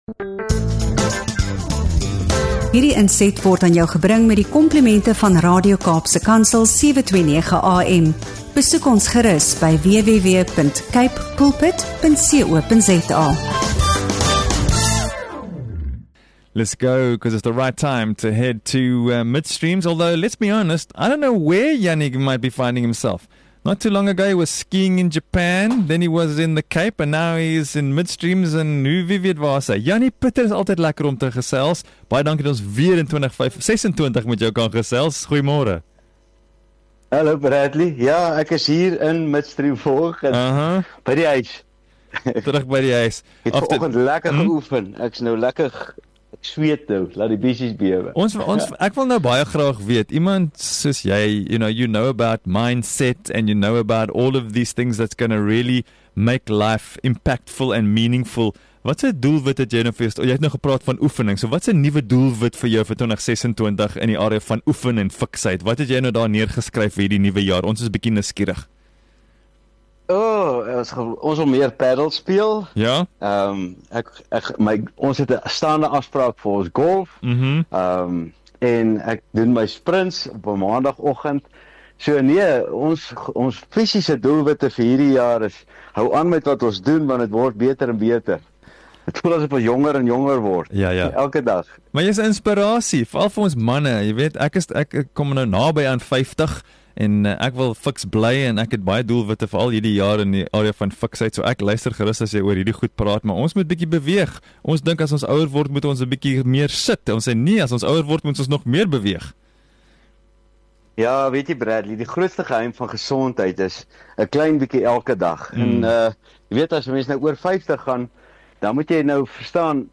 Ons praat oor hoekom klein, daaglikse oefening meer krag het as uiterstes, hoe dissipline soos vroeg opstaan jou hele week kan verander, en waarom dit belangrik is om na oefening uit te sien eerder as om dit uit te stel. Die gesprek beweeg ook na iets selfs kragtiger: die woorde wat ons spreek.